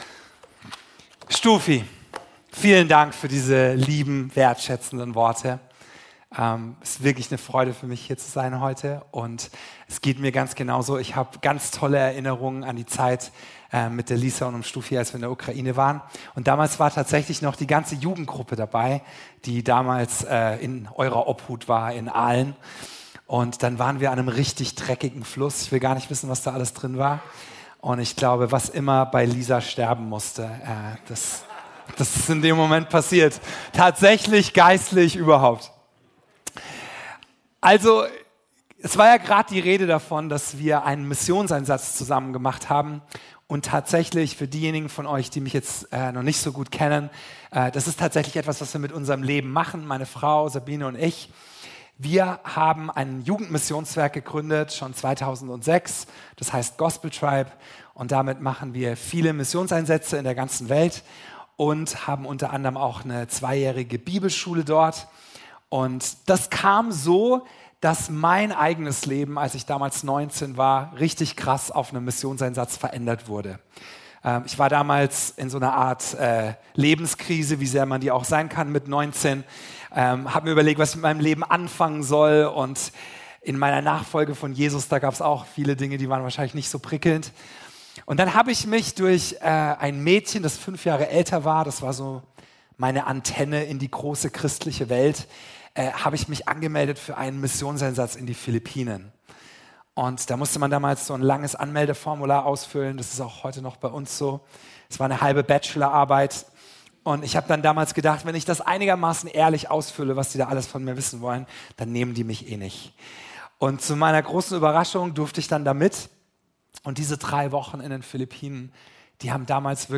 Dienstart: Gottesdienst - Sonntagmorgen